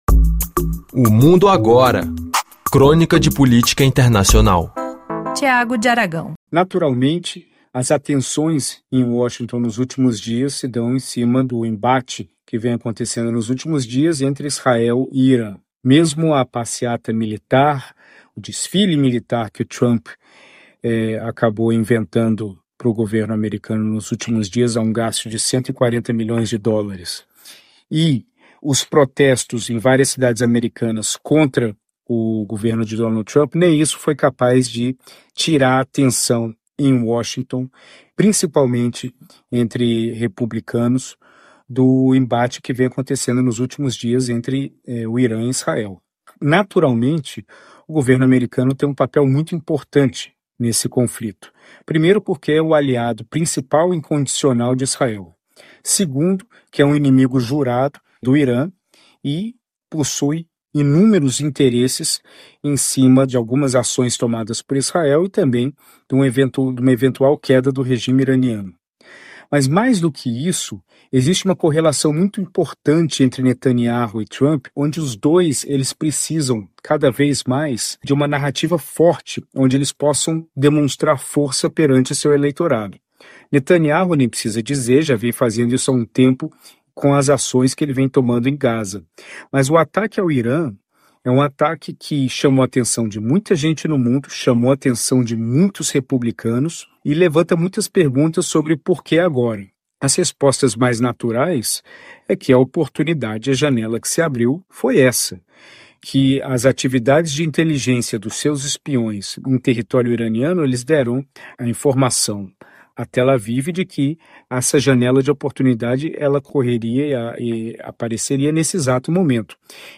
Crônica semanal de geopolítica internacional.